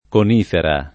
conifera [ kon & fera ] s. f.